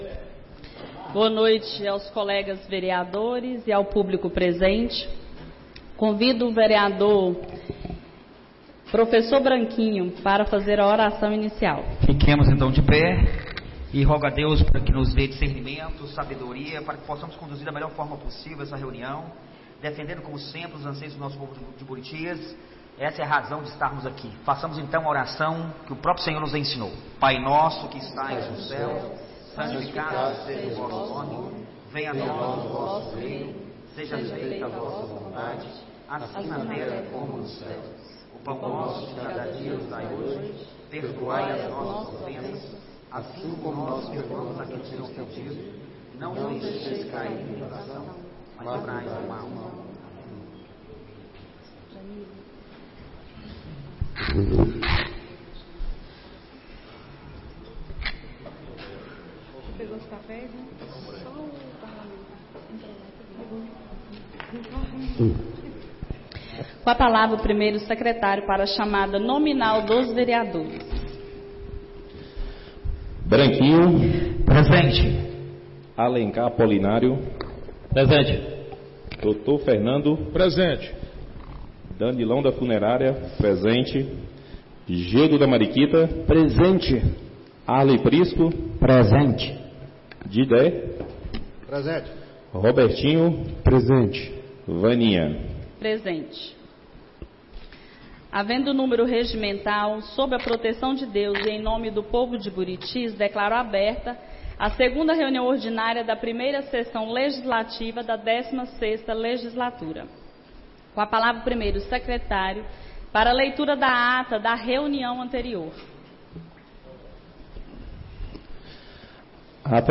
2ª Reunião Ordinária da 1ª Sessão Legislativa da 16ª Legislatura - 27-01-25